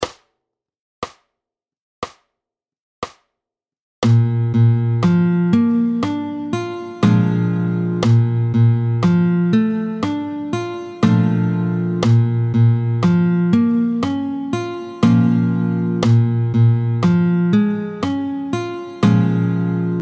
28-05 Variations sur la forme de La, tempo 70